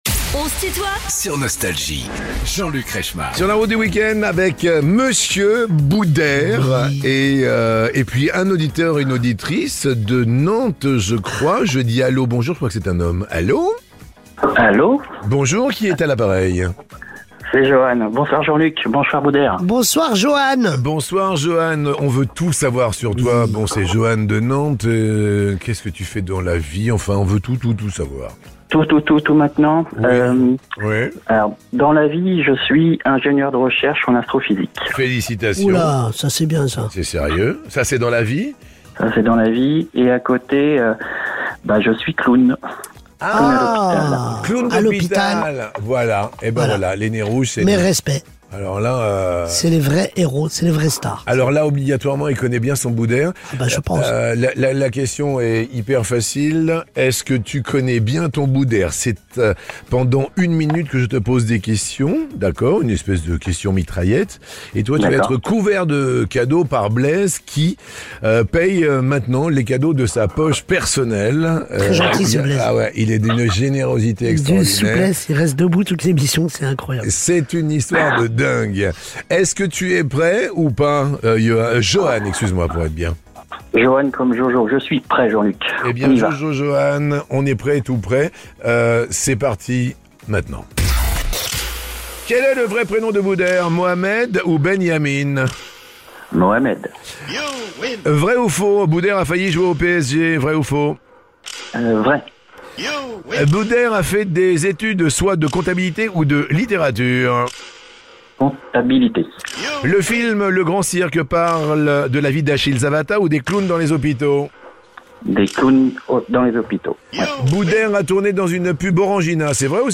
Booder, l’humoriste au grand cœur est l'invité de "On se tutoie ?..." avec Jean-Luc Reichmann